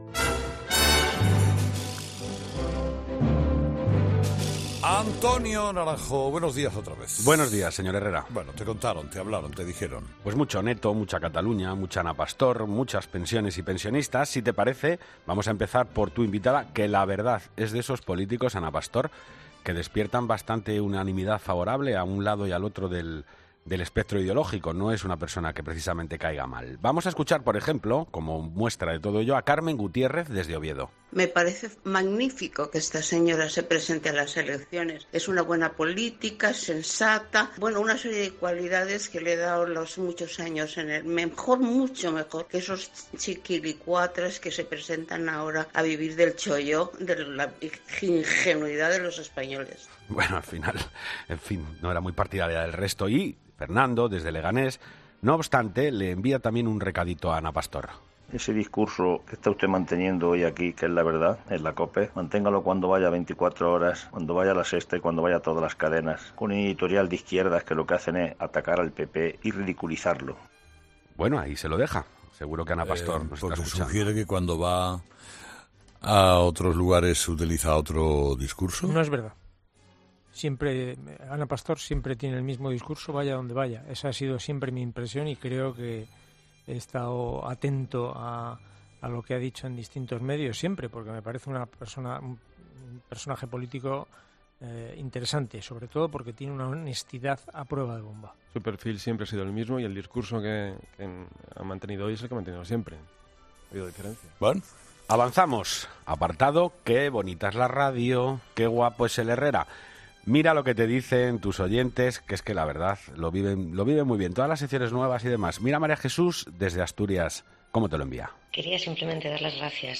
Avalancha de mensajes en el contestador, la audiencia volcada en mostrar su opinión sobre Ana Pastor, Cataluña y las pensiones.